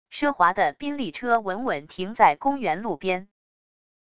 奢华的宾利车稳稳停在公园路边wav音频